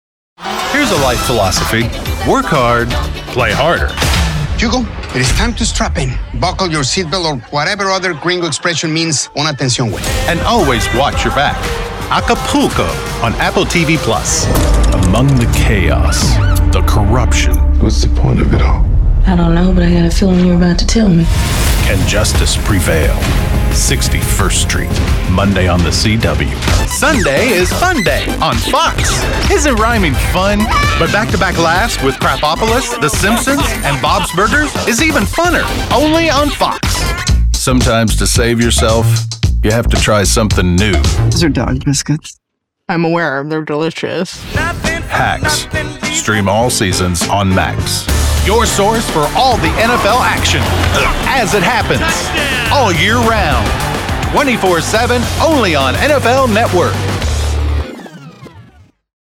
Television
Television-Promos.mp3